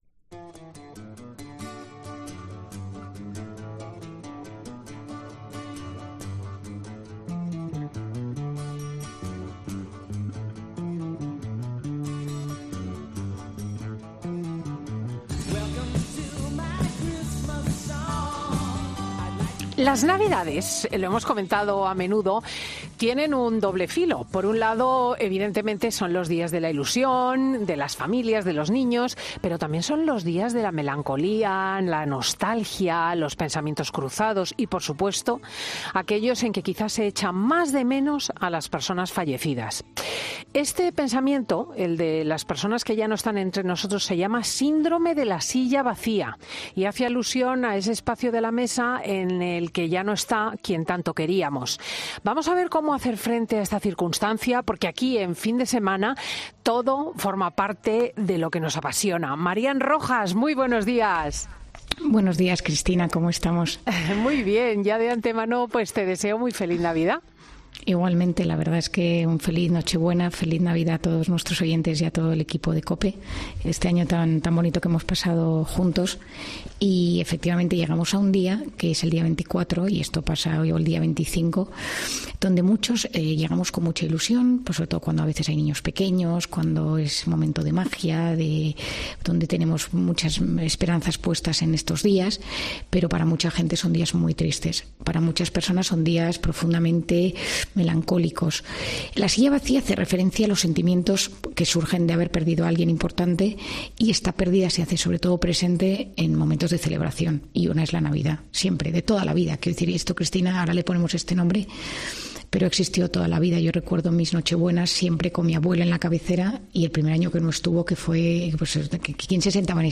Marian Rojas, nuestra psiquiatra, nos ha hablado hoy sobre ese síndrome.